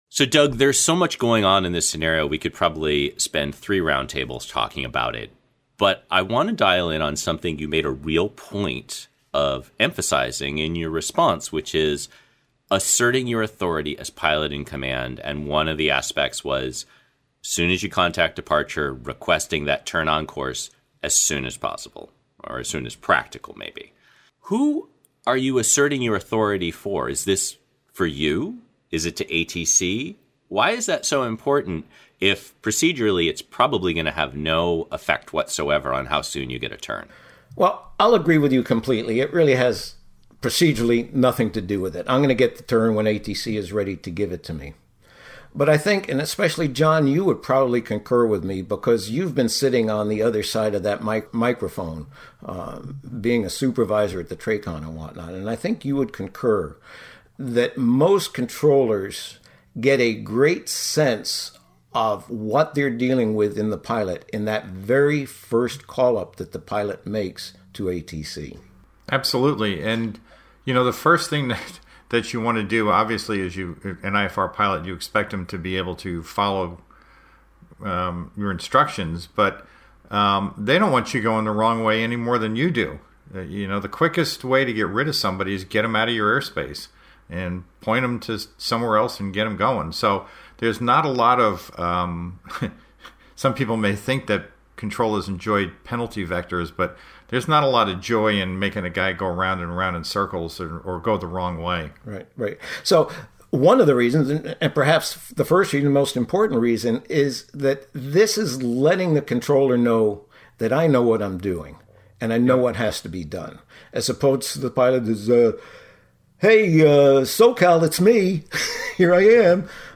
Listen in as our team of instructors discuss and debate the details of this scenario.